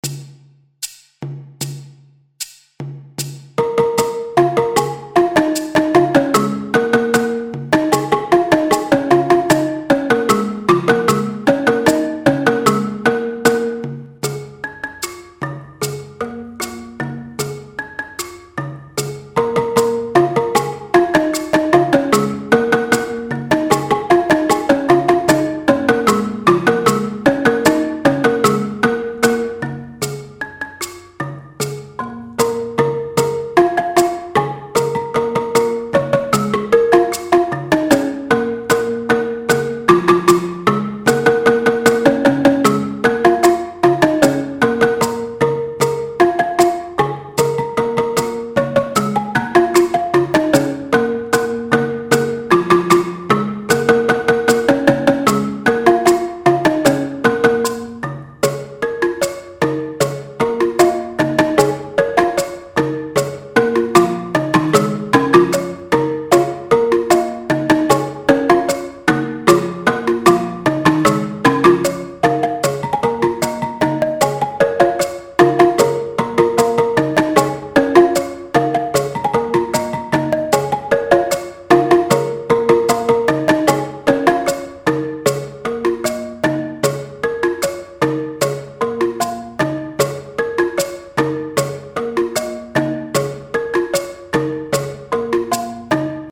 Pentatonische balafoon
152 bpm Arrangement
RitmeMuso-Bwe-152bpm.mp3